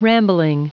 Prononciation du mot rambling en anglais (fichier audio)
Prononciation du mot : rambling
rambling.wav